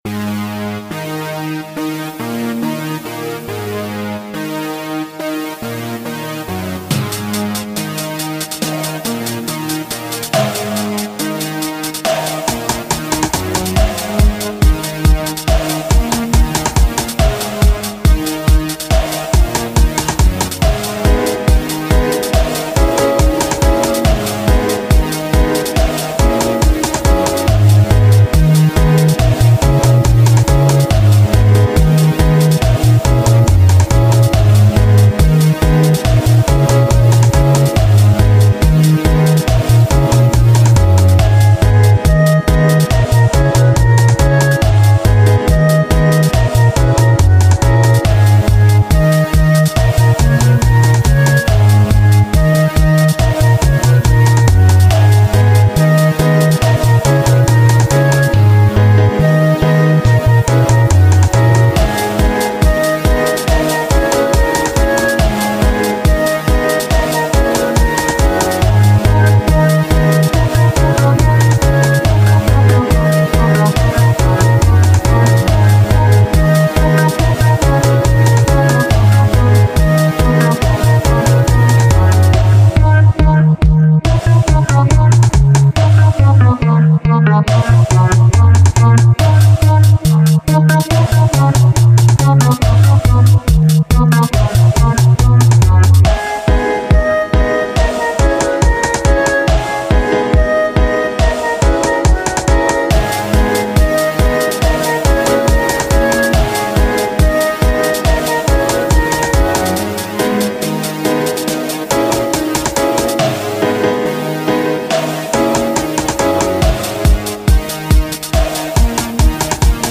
i made this song on logic pro . it's one of my first song.